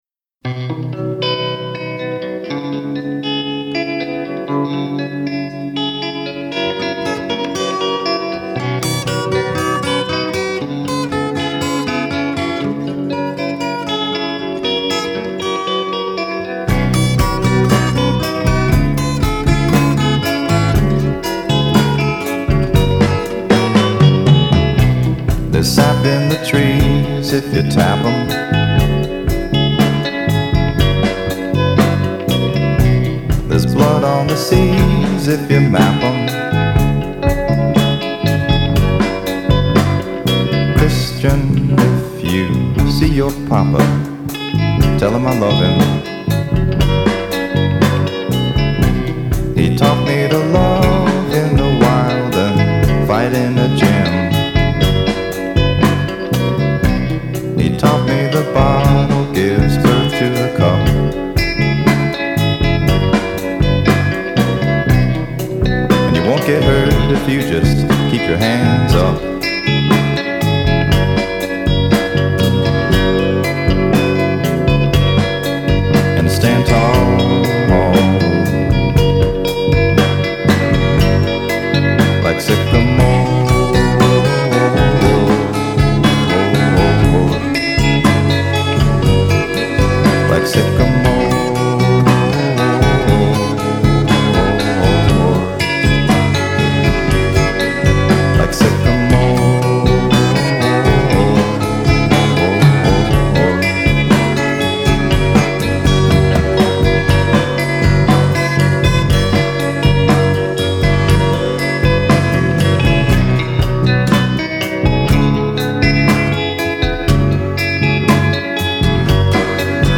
American Alt Country/Folk